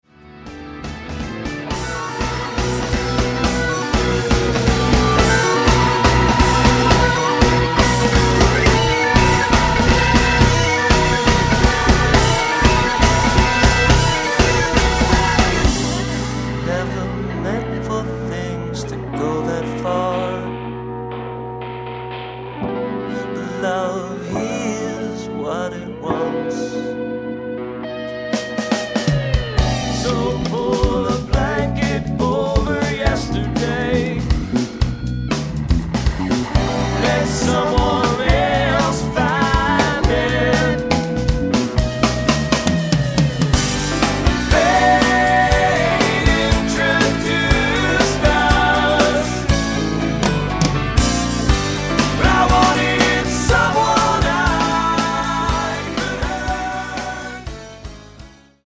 keyboards, backing vocals
guitars, lead and backing vocals
drums, percussion, backing vocals
bass, backing vocals